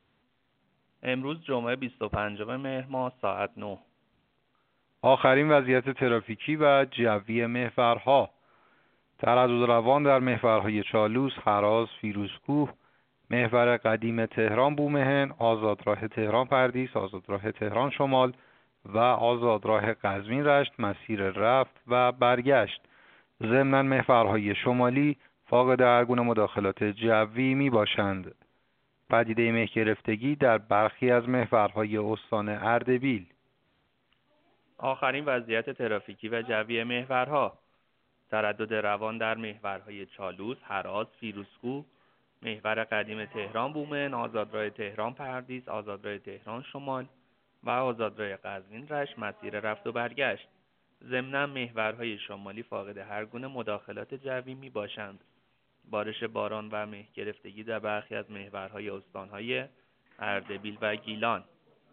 گزارش رادیو اینترنتی از آخرین وضعیت ترافیکی جاده‌ها ساعت ۹ بیست‌وپنجم مهر؛